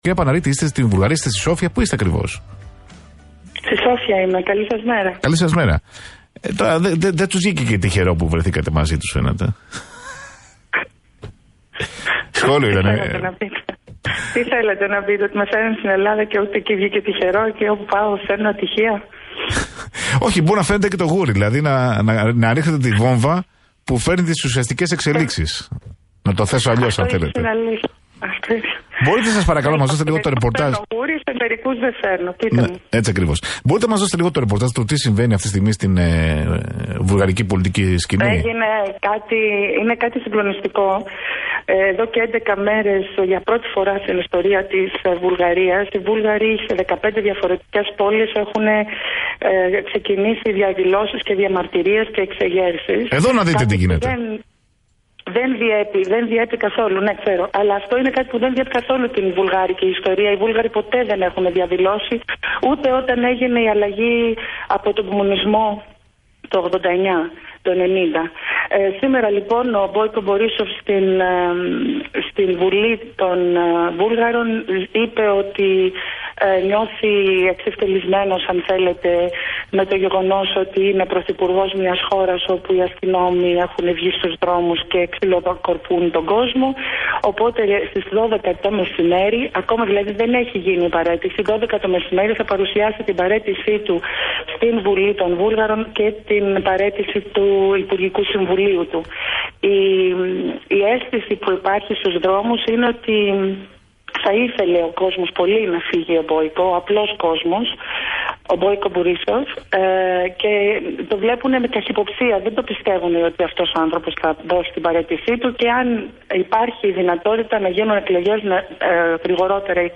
Για την παραίτηση της κυβέρνησης της Βουλγαρίας ζωντανά από τη Σόφια